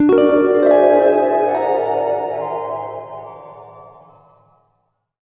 GUITARFX17-L.wav